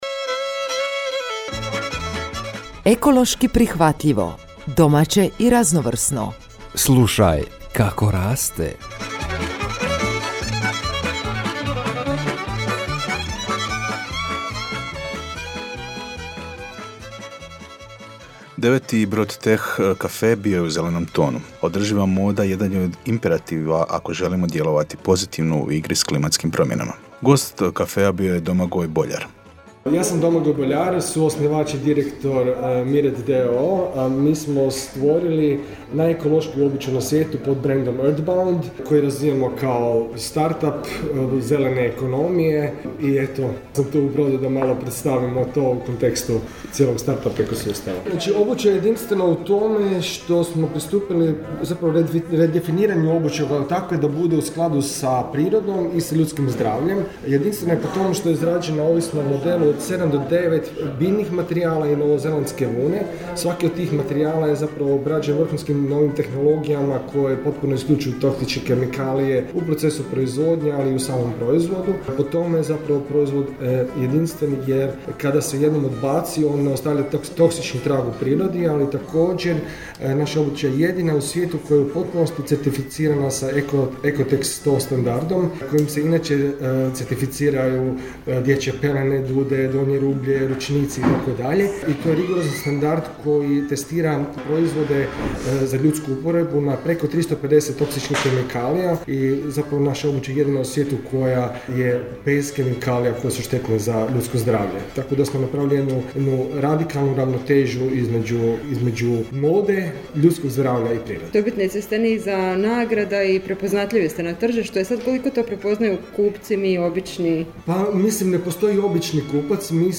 Njihove tenisice napravljene su od 97 posto prirodnih materijala. Ovaj edukativni sadržaj objavljujemo u obliku radijske emisije koju možete uvijek ponovno poslušati.